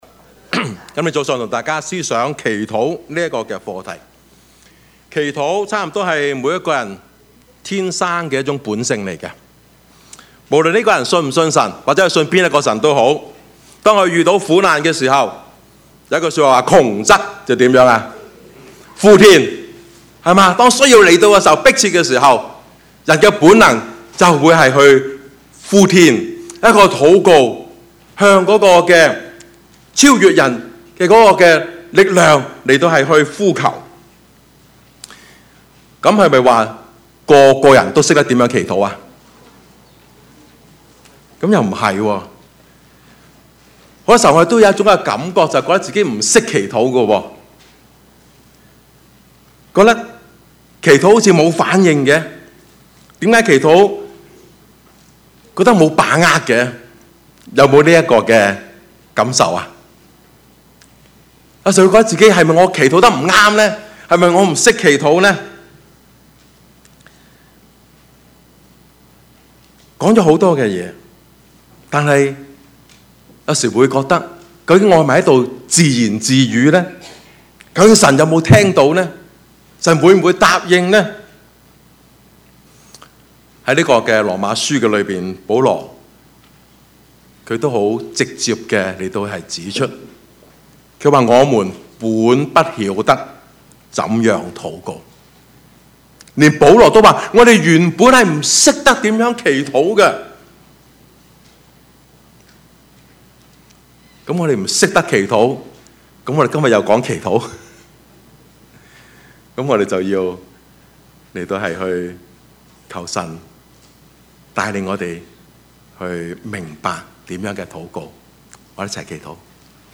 Service Type: 主日崇拜
Topics: 主日證道 « 保羅､巴拿巴和約翰馬可 真假信徒 »